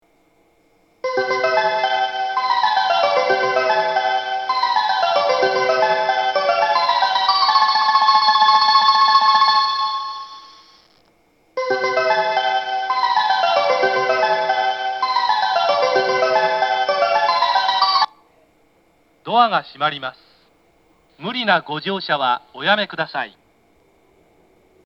発車メロディー
1.8コーラスです!入線列車が少ないです。